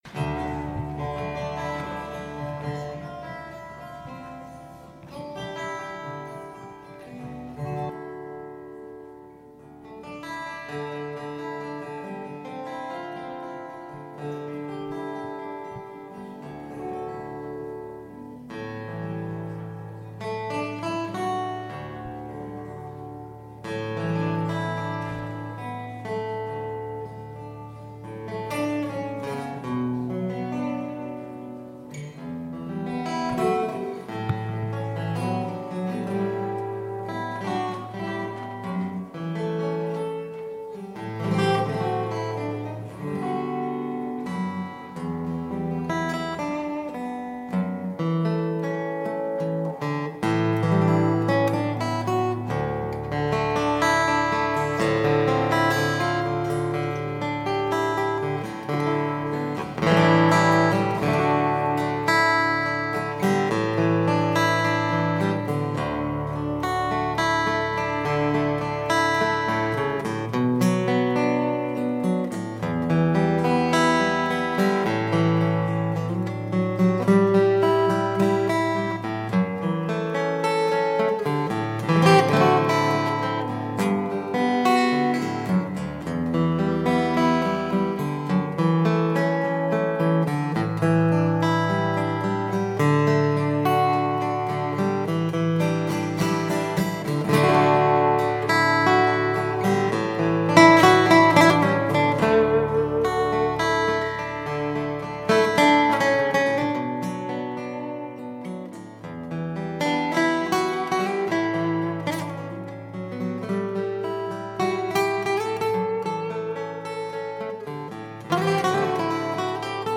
Audiodokumentation Bundeskongress 2017: „Erinnern und Zeichen setzen! – Zeugnisse politischer Verfolgung und ihre Botschaft.“ 28./29./30. April 2017, Magdeburg, Maritim Hotel (Teil 1: 28. April)